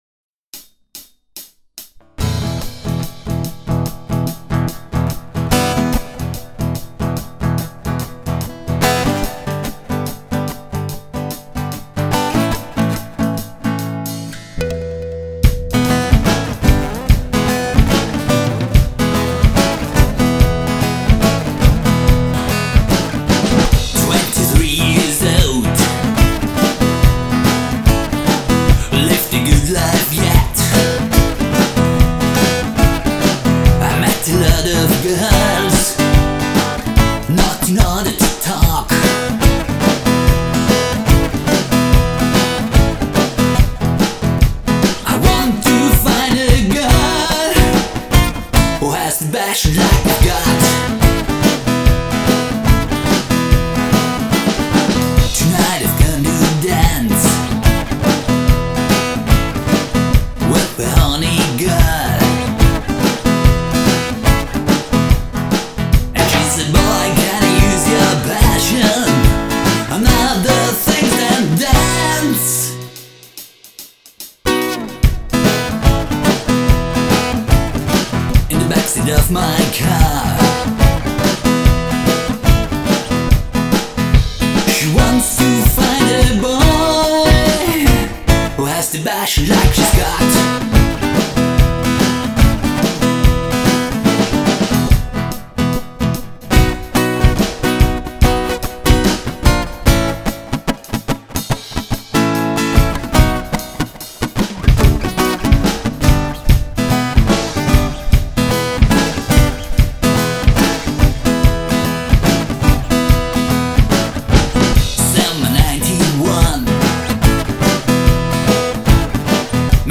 Simple song with just acoustic guitar, my voice and Jamstix